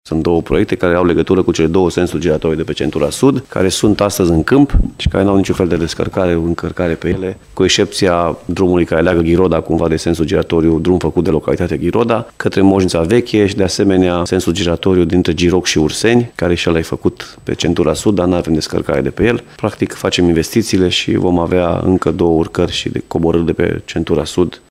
Președintele CJ Timiș, Alfred Simonis, a explicat că sensurile giratorii sunt deja amenajate pe centură, fiind nevoie doar de lucrări la drumurile de acces.